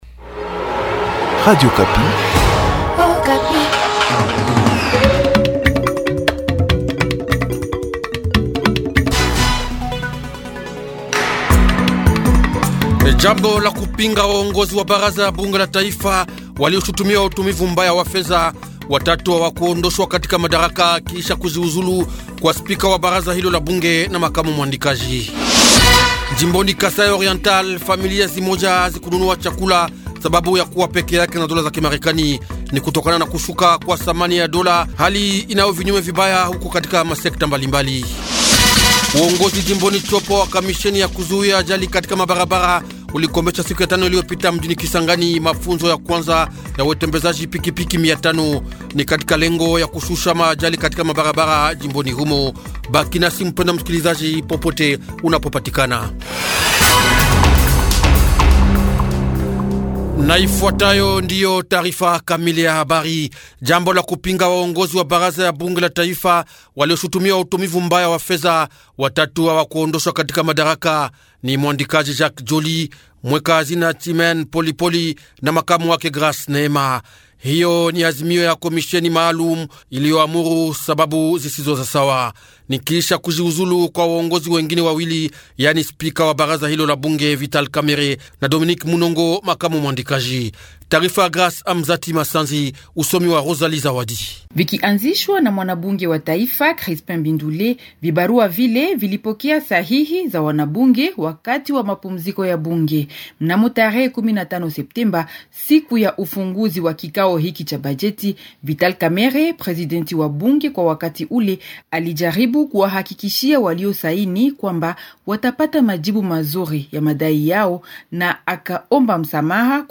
Journal Swahili
Edition de lundi le 29 Septembre 2025 Matin 05h05